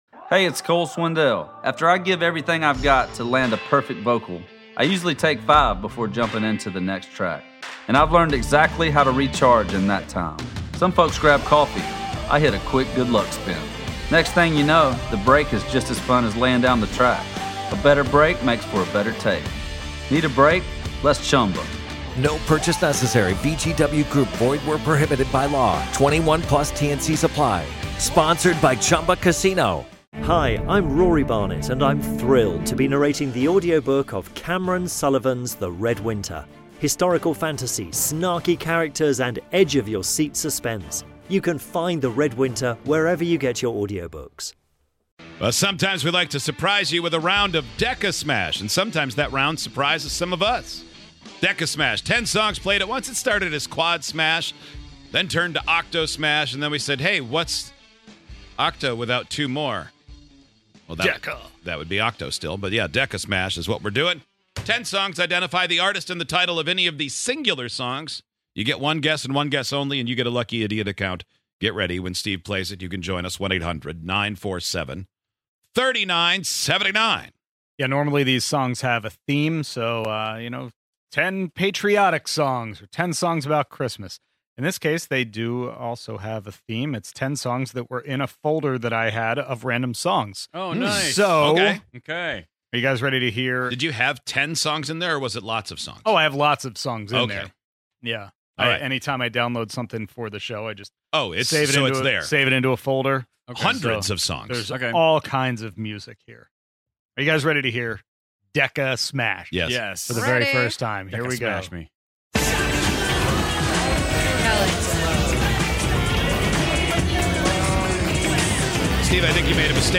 put together a list of ten songs, smashed them all together, and played them at the same time. Can you guess any of the 10 songs from DECASMASH??